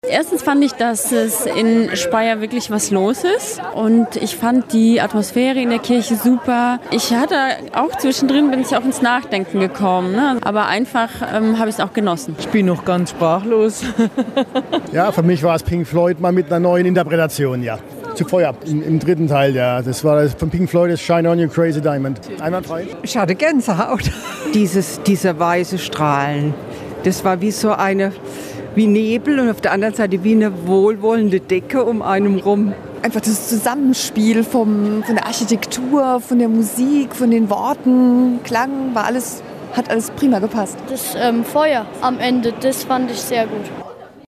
Zuschauerstimmen - eingefangen von einer Reporterin des Senders RPR1